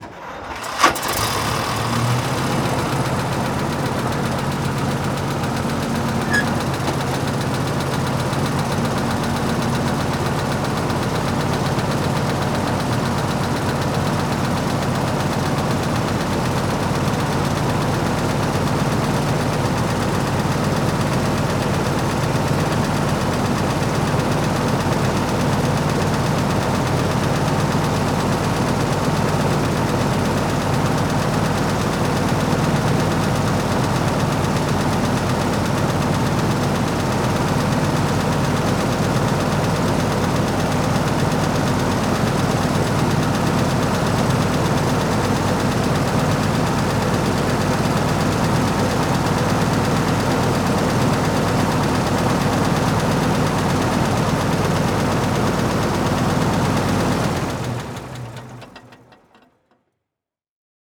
Tank Half Track Sound
transport
Tank Half Track